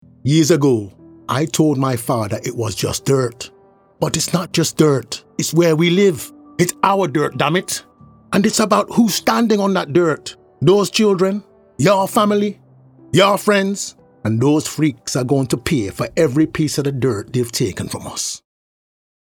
Animation. Jamaican, Imaginative, Distinctive, Characters